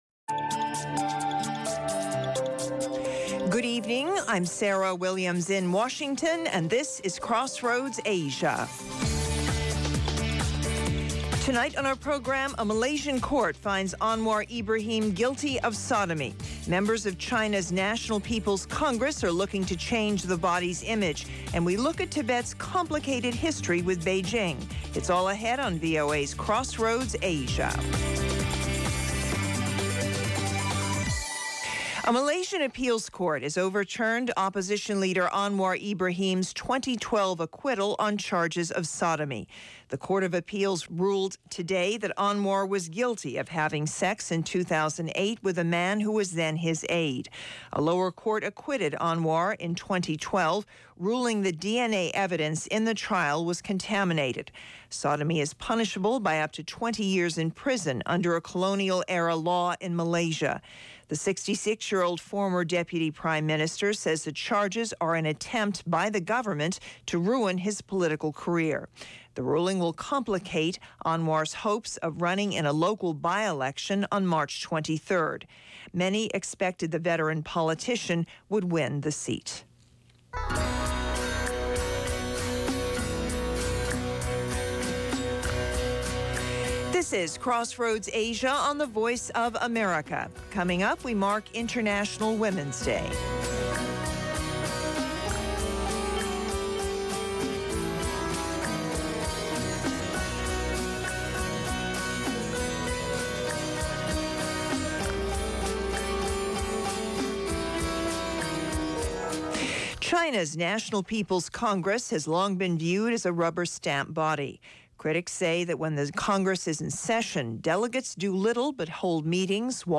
Crossroads Asia offers unique stories and perspectives -- with in-depth interviews, and analysis.